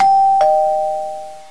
doorbell.wav